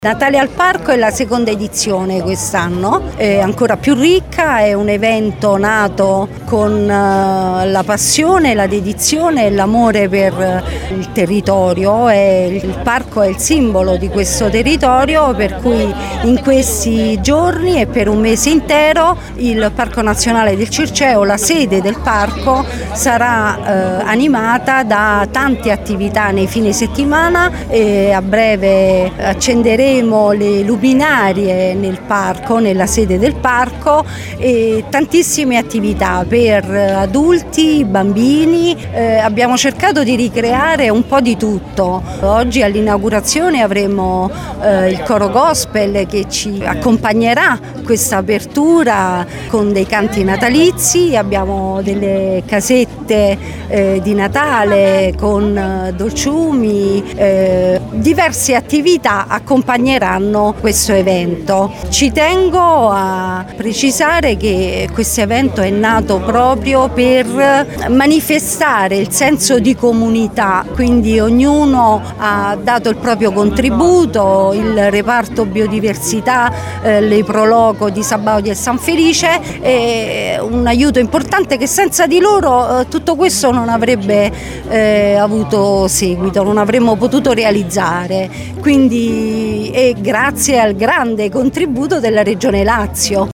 Presente all’inaugurazione delle luminarie la Presidente del Parco Nazionale del Circeo, Emanuela Zappone: